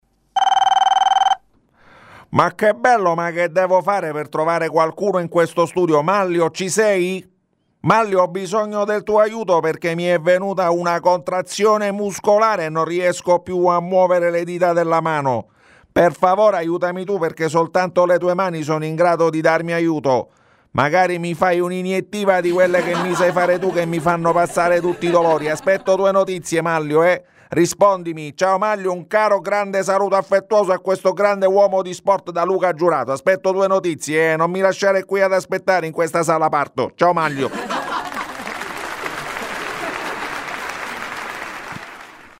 Ancora una pagina di audio libro